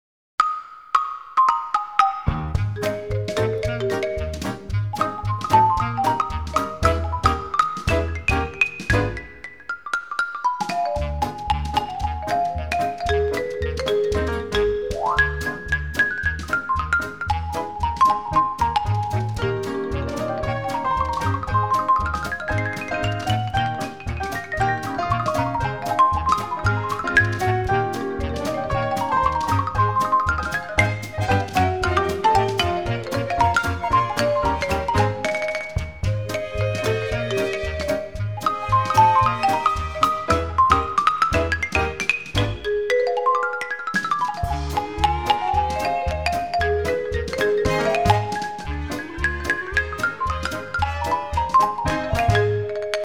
Eccezionale percussionista